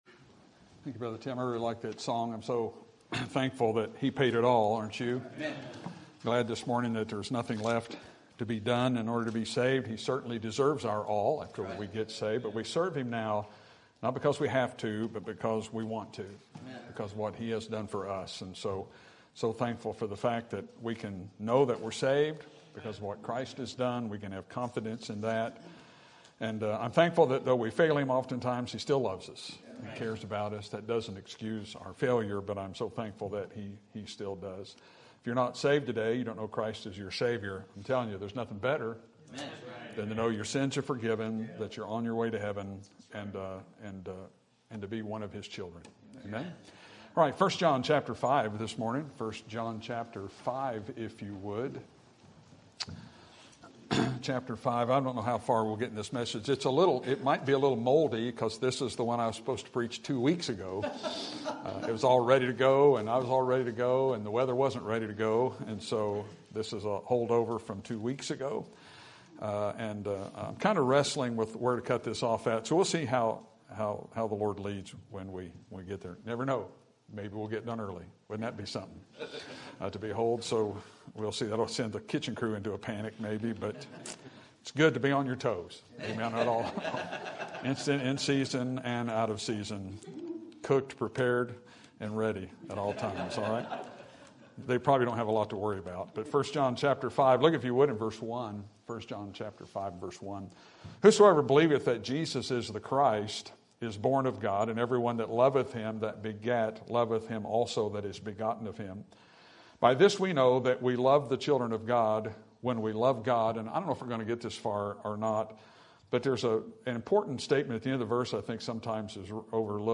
Sermon Date: Sunday, January 12, 2025 - 11:00am Sermon Title: Who is My Brother?